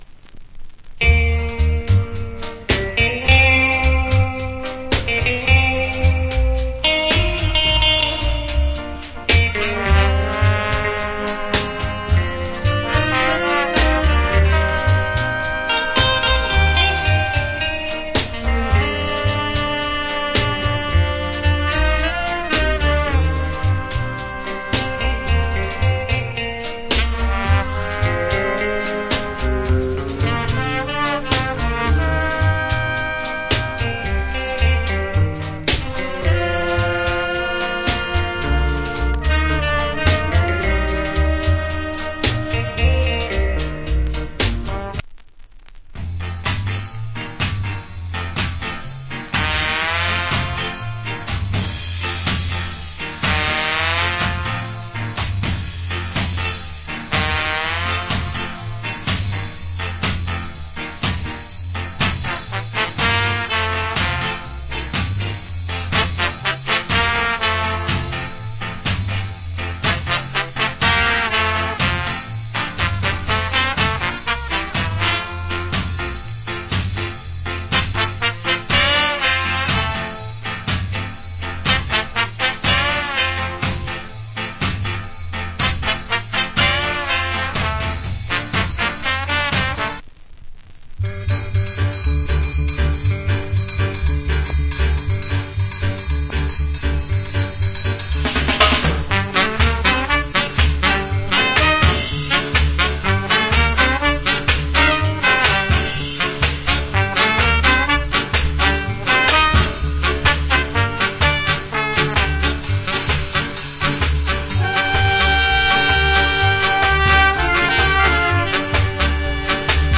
Ska Inst.